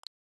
Button03.wav